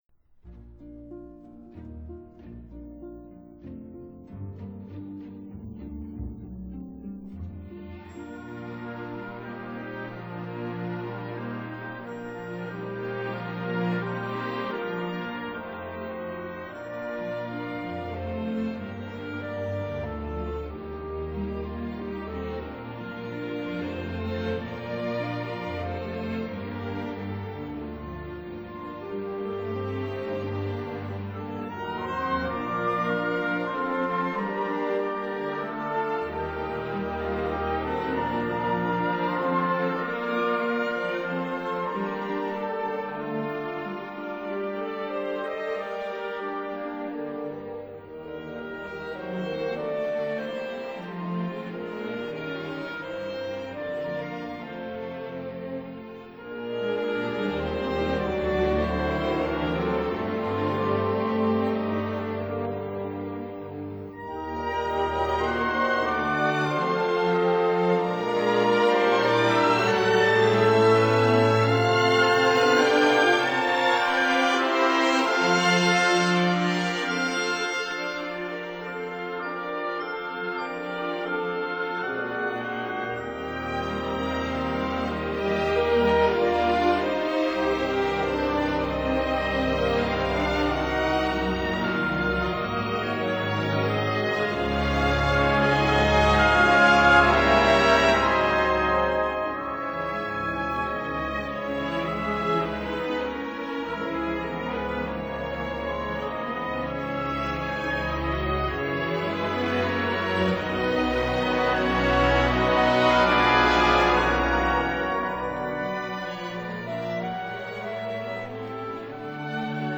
(version for orchestra)